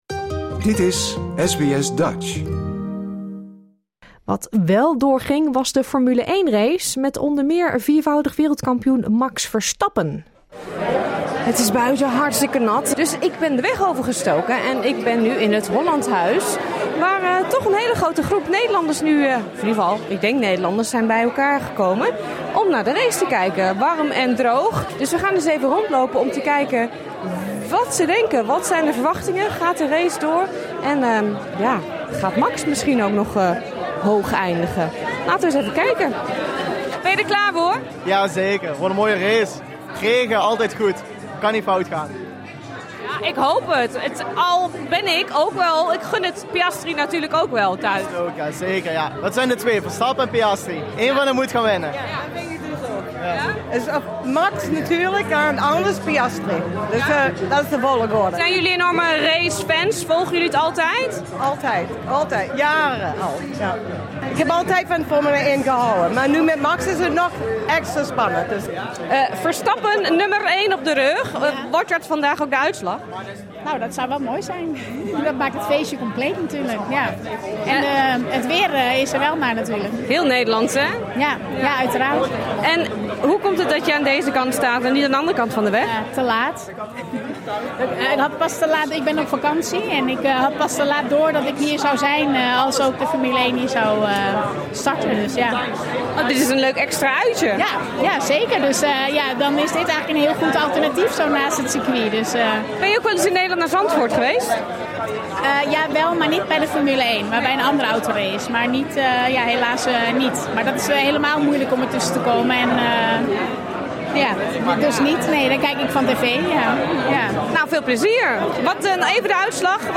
De F1 race ging zondag ondanks de vele regen gewoon door. Wij peilden het vertrouwen in Max Verstappen onder de bezoekers van het Holland House.